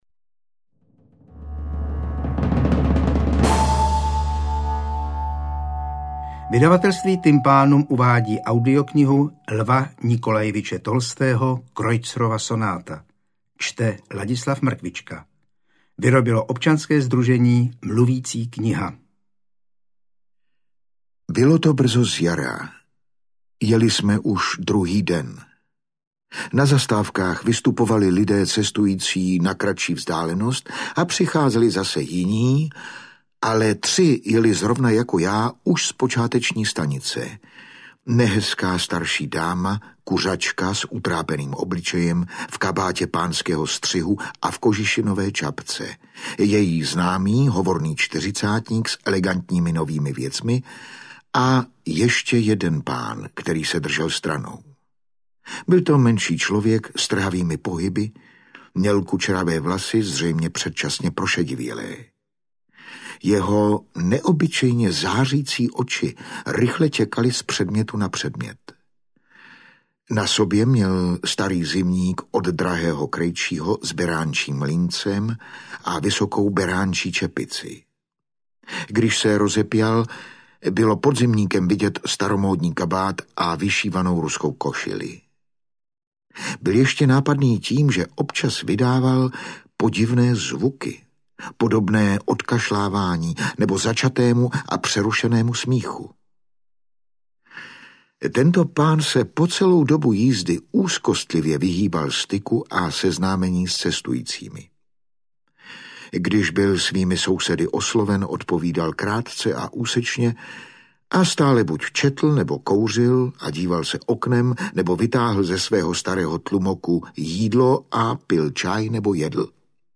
Interpret:  Ladislav Mrkvička
AudioKniha ke stažení, 31 x mp3, délka 3 hod. 50 min., velikost 209,1 MB, česky